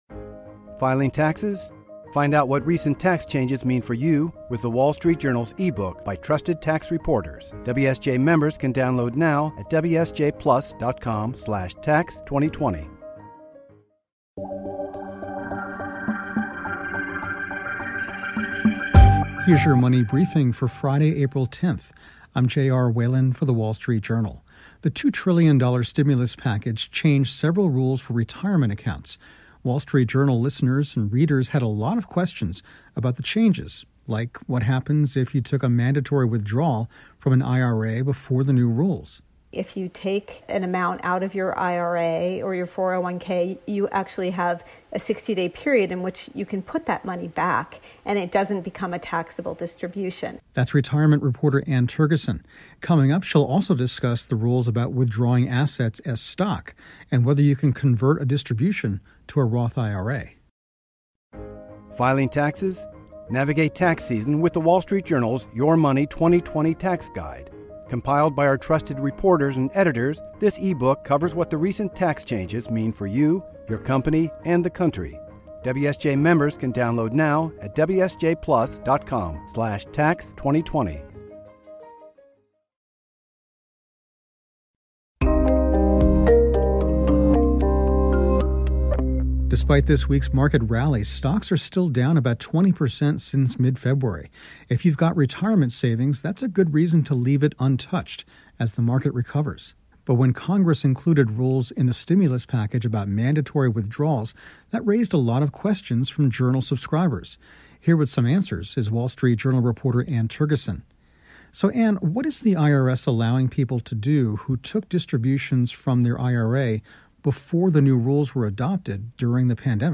answers questions from WSJ subscribers about rules included in the stimulus package regarding mandatory withdrawals from IRAs and 401(k) accounts.